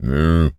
pgs/Assets/Audio/Animal_Impersonations/cow_moo_06.wav at master
cow_moo_06.wav